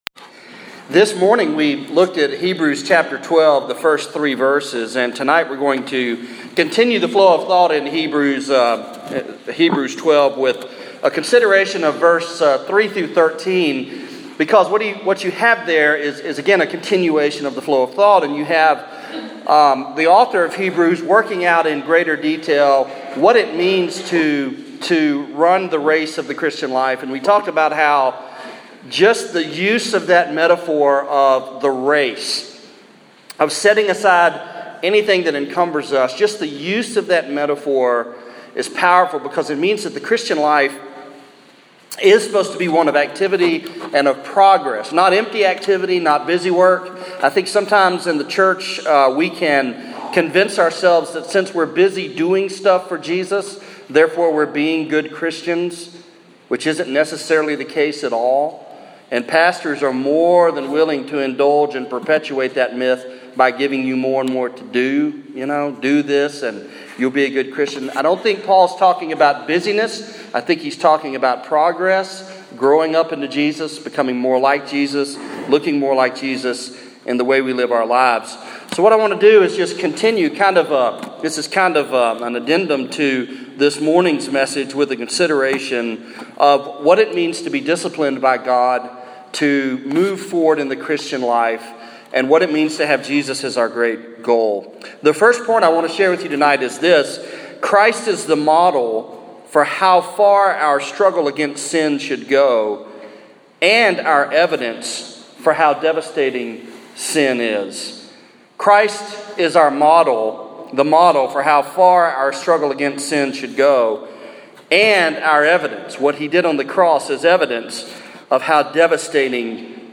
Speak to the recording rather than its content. Preached on April 12, 2015, at Central Baptist Church, North Little Rock, AR